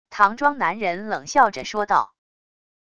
唐装男人冷笑着说道wav音频